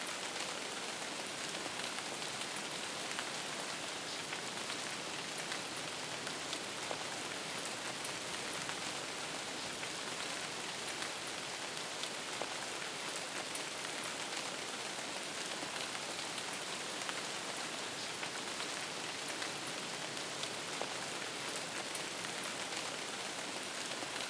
RainLoop.wav